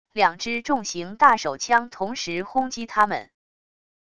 两只重型大手枪同时轰击他们wav音频